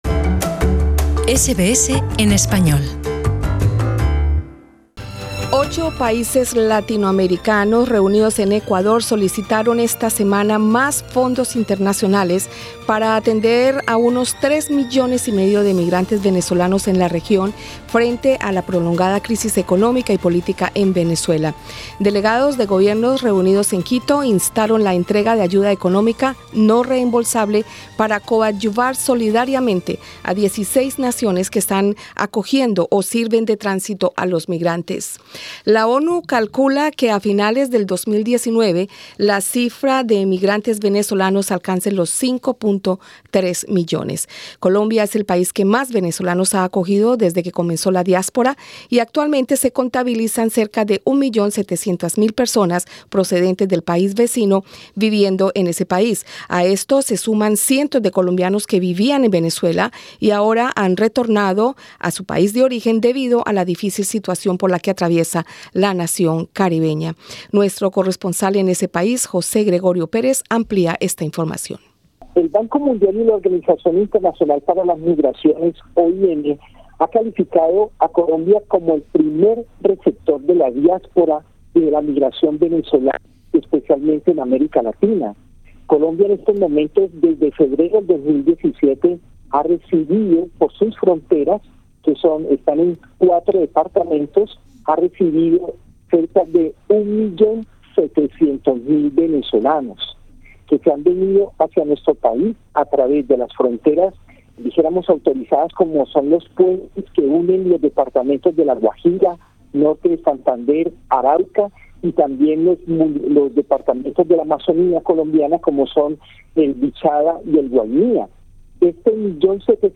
SBS en español